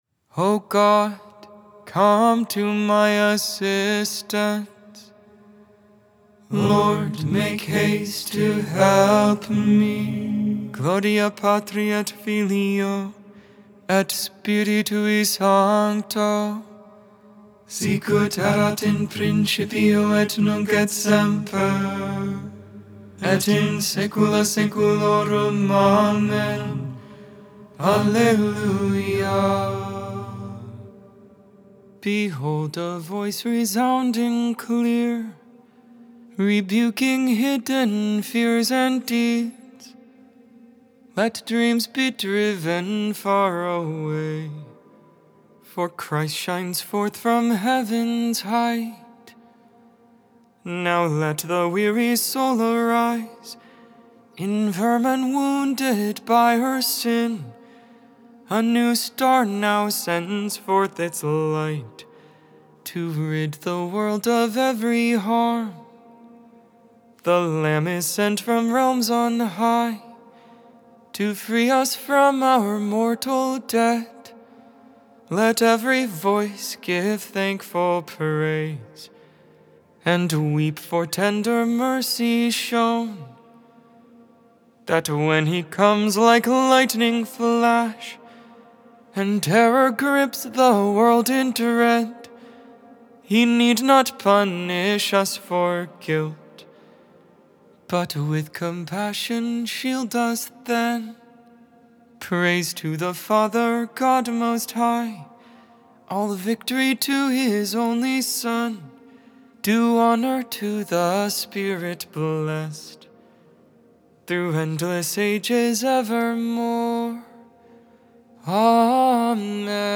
Lauds, Morning Prayer for the First Tuesday in Advent, December 3, 2024.